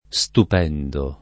[ S tup $ ndo ]